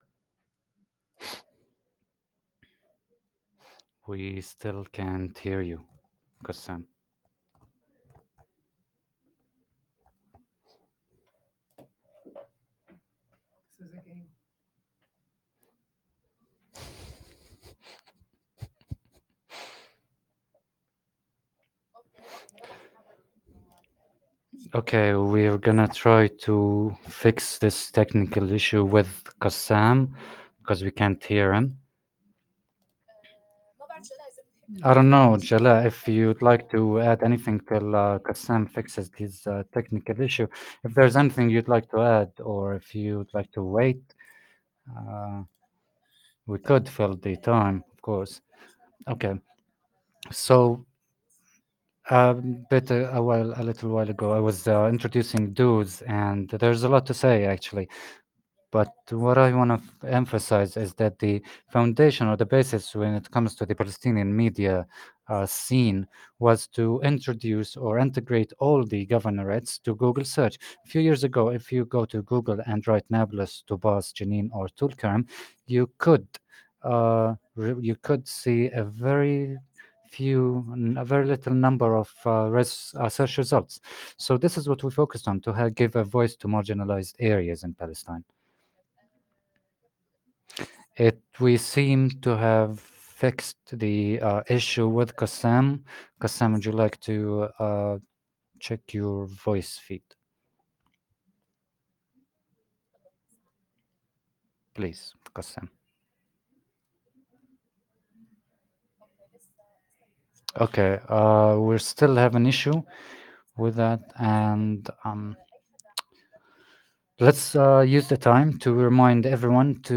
English Interpretation